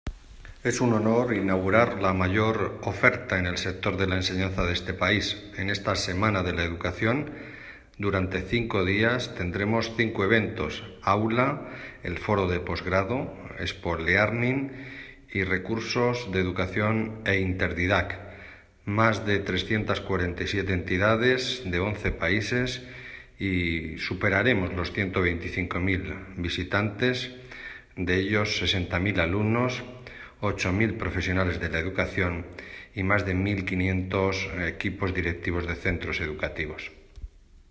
Declaraciones del secretario de Estado de Educación, Formación Profesional y Universidades, Marcial Marín, tras su visita al Salón Internacional del Estudiante y de la Oferta Educativa, AULA 2016.